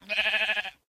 sounds / mob / sheep / say2.ogg